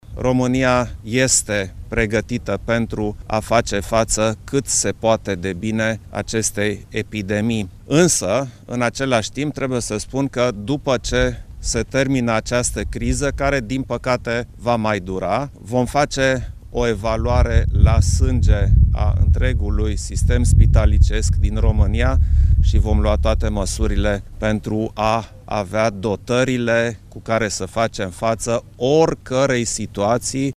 Şeful statului a vizitat Spitalul Militar de Campanie ROL 2, amenajat în incinta Institutului Ana Aslan din Otopeni.
Preşedintele Klaus Iohannis a reluat apelul făcut cetăţenilor să respecte normele de igienă şi să evite contactul cu alţi oameni: